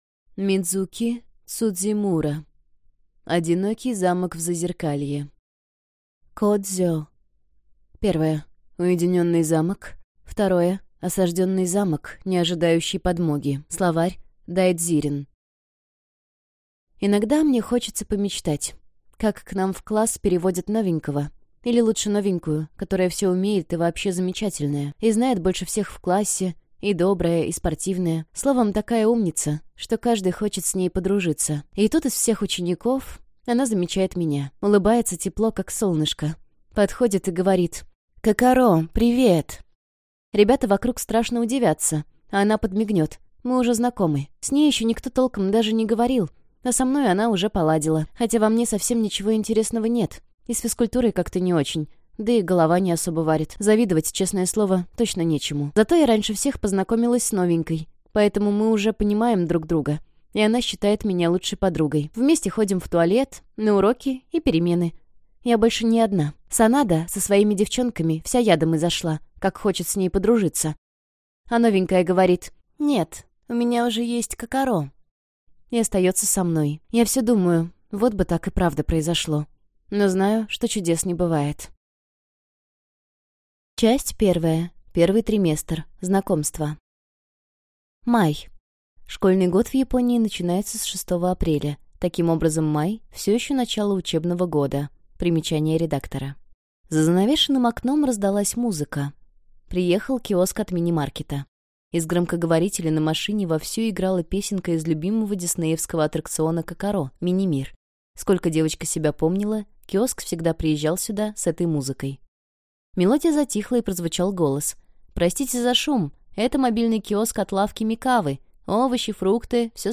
Аудиокнига Одинокий замок в зазеркалье | Библиотека аудиокниг